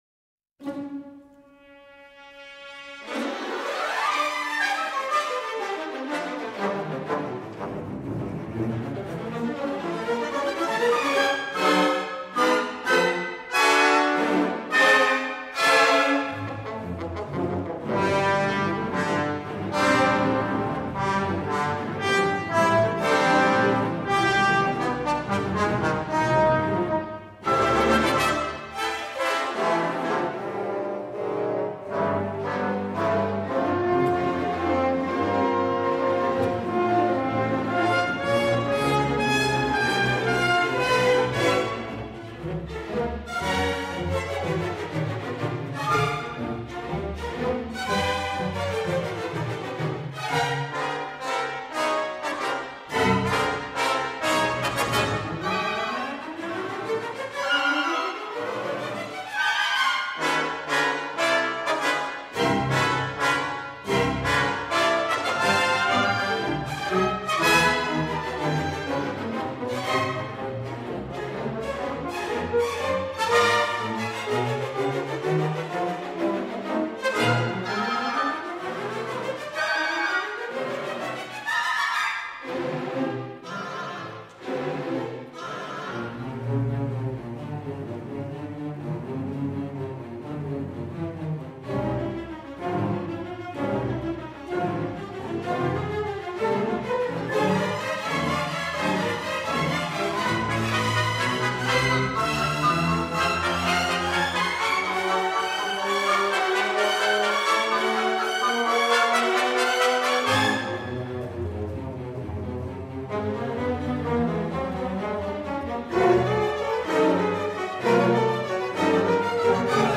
Neo-Classical / Modern.